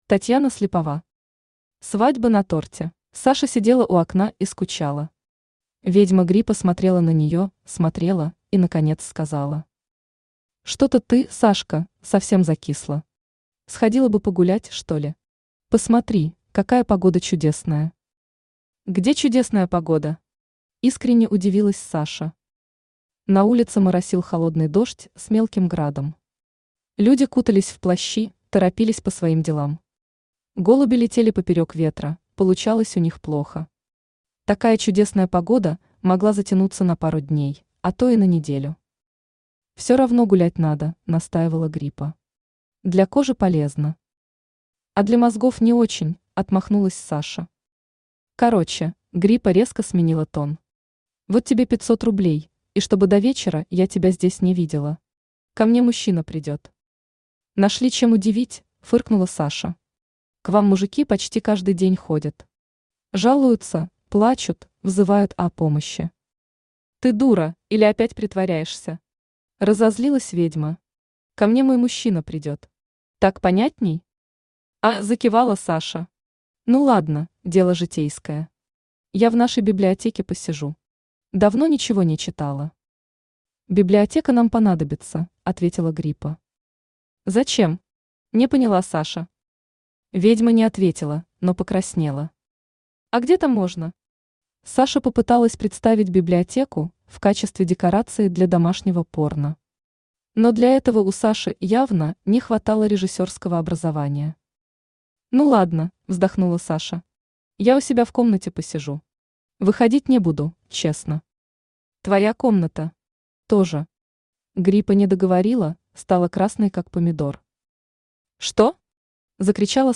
Аудиокнига Свадьба на торте | Библиотека аудиокниг